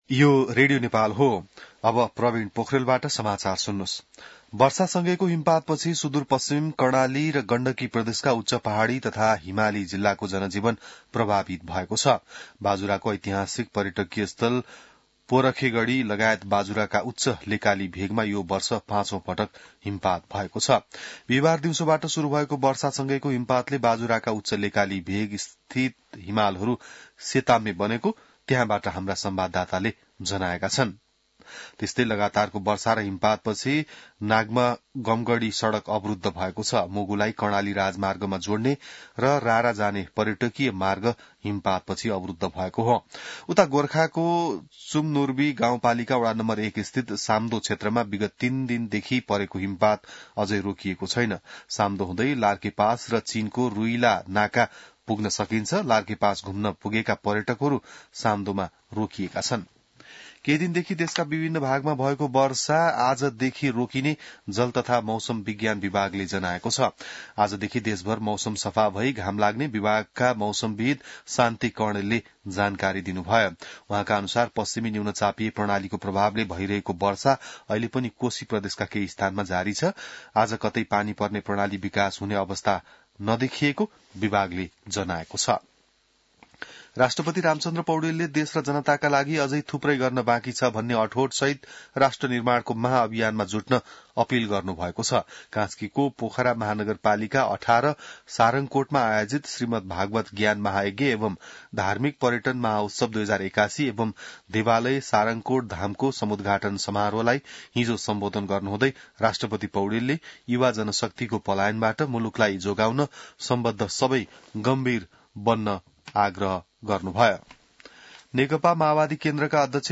बिहान ६ बजेको नेपाली समाचार : १९ फागुन , २०८१